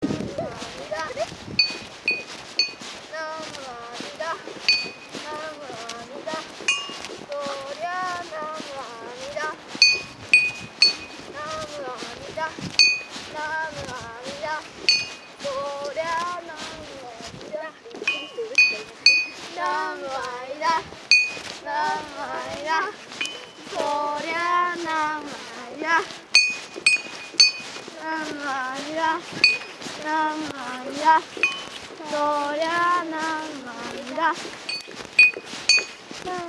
寒念仏
集落内を回る際に唱えられるのは「なむあみだ　なむあみだ　そうりゃーなむあみだ」であり、小さな鉦を叩きながらこれを繰り返し唱えて回る。
現在は三九郎が塩川原だけではなく、北隣の原と県営住宅のある地区と一緒に行われているため、この寒念仏もそれらの地域の子ども達によって行われている。
kannenbutu.MP3